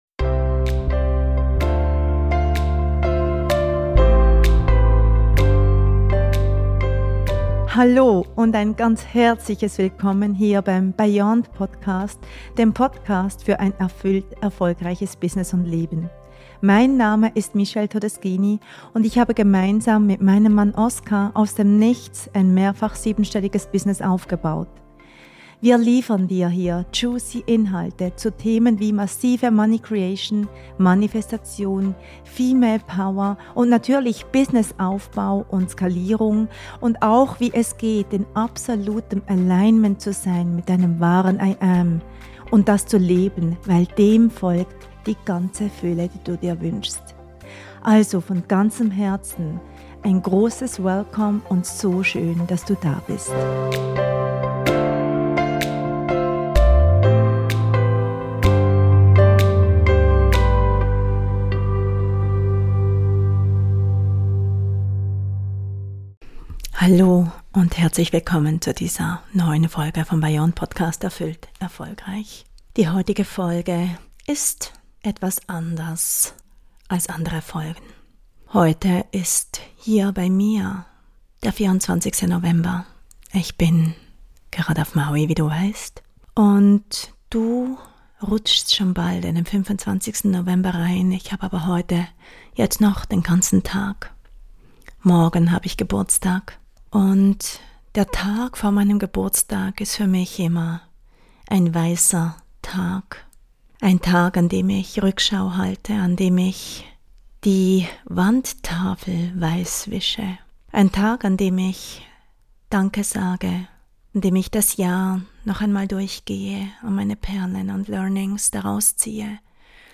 Sie ist roh und ungefiltert.